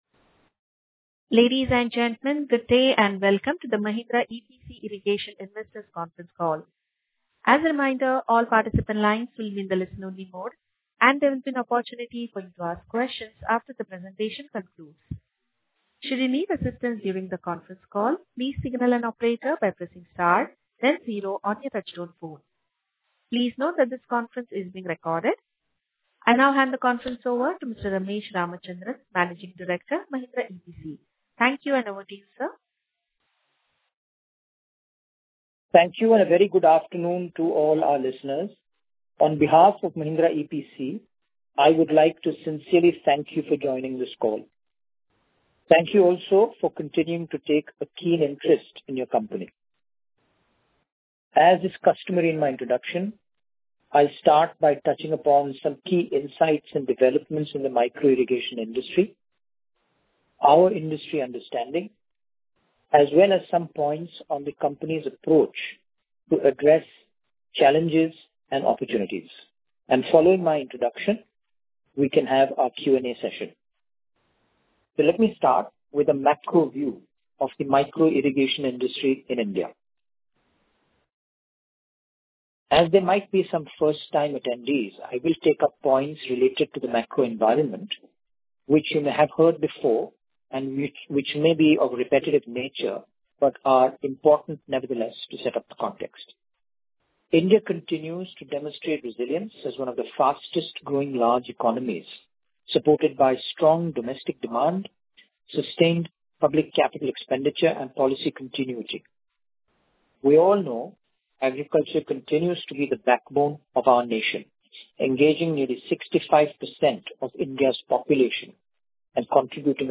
Audio-Recording-of-Investor-Call-H1-F26.mp3